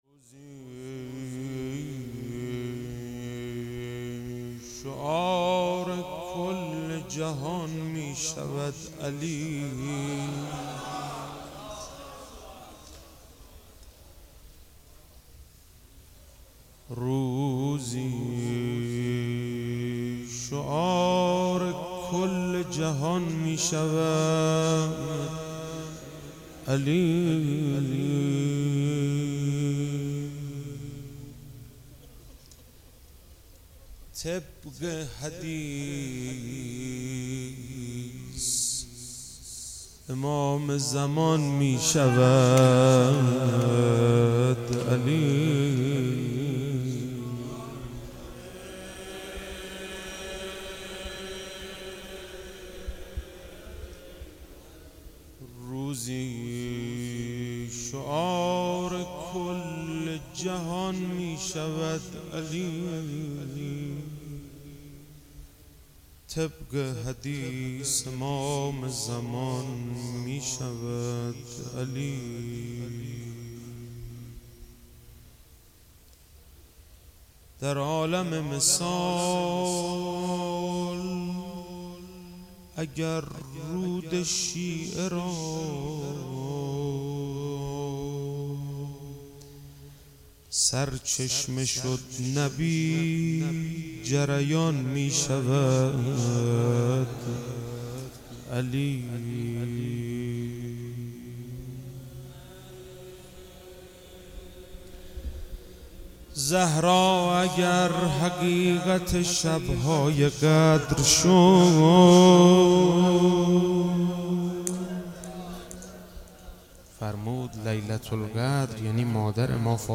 روضه - روزی شعار کل جهان میشود علی